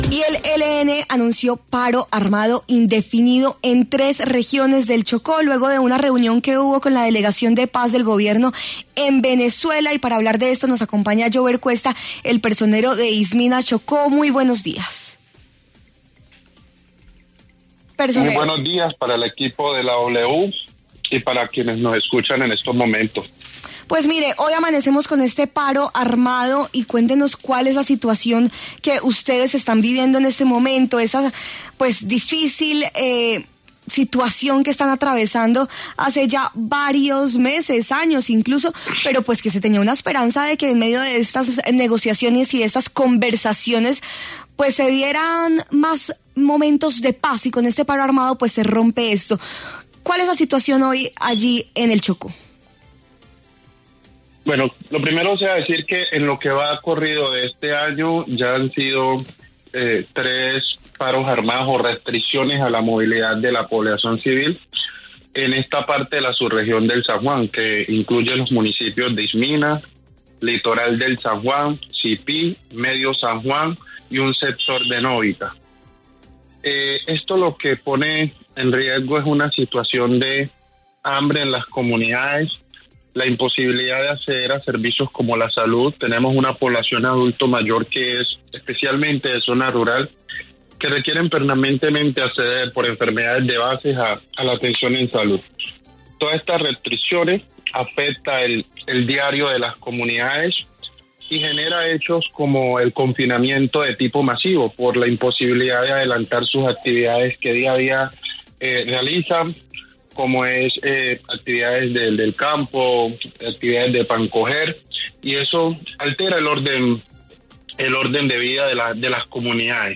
En diálogo con W Fin De Semana, el personero de Istmina, Chocó, Yuver Cuesta, se refirió al nuevo paro armado que anunció el Ejército de Liberación Nacional (ELN) en esa región del país y que incomunica a la población.